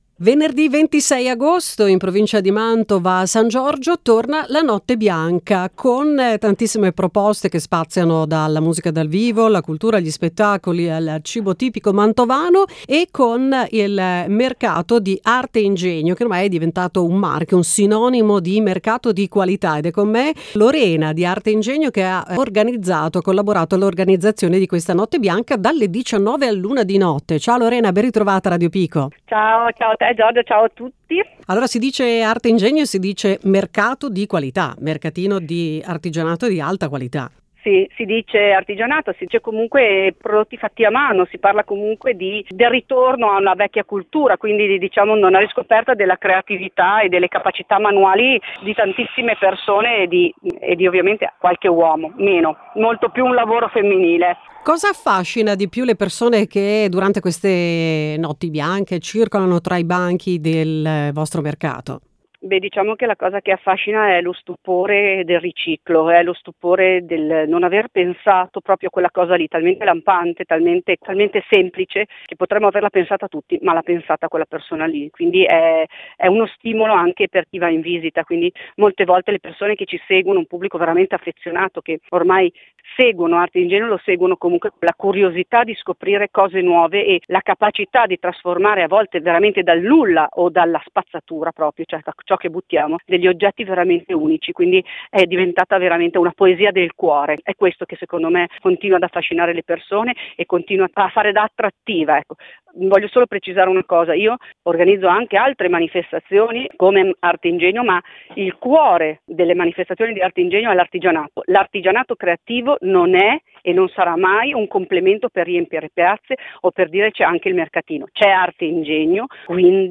Ecco la presentazione dell’evento, nell’intervista della redazione